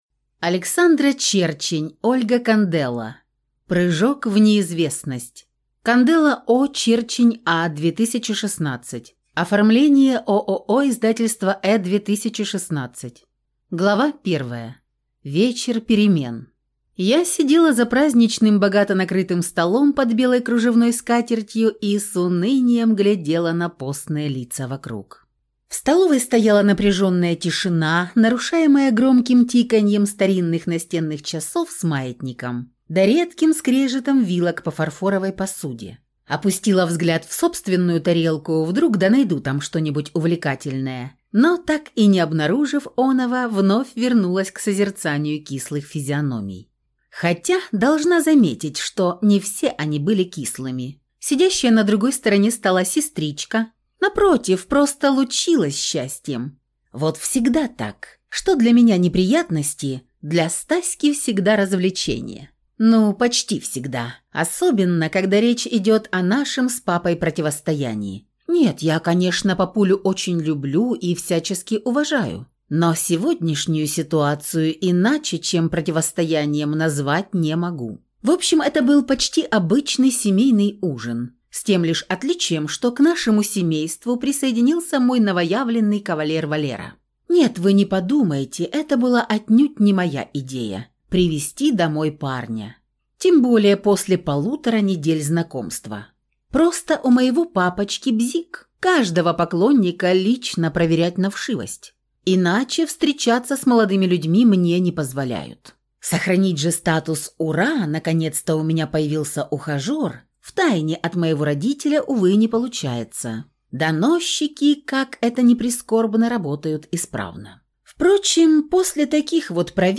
Аудиокнига Прыжок в неизвестность | Библиотека аудиокниг